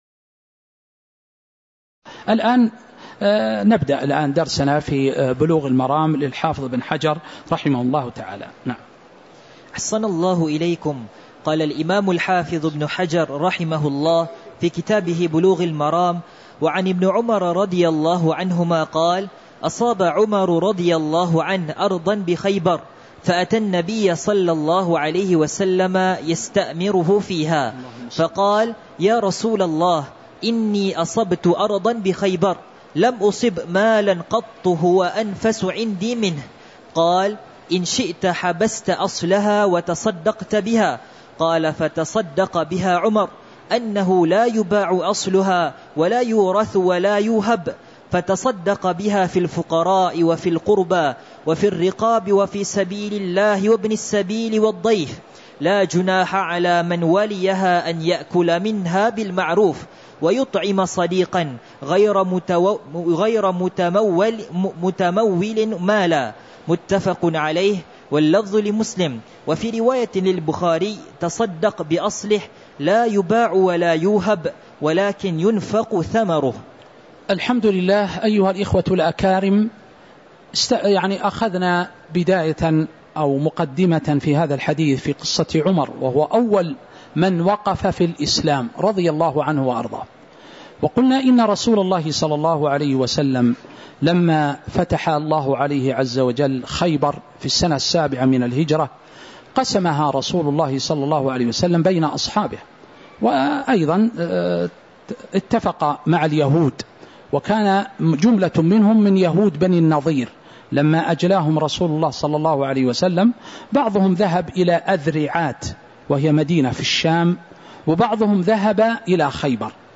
تاريخ النشر ٦ رجب ١٤٤٦ هـ المكان: المسجد النبوي الشيخ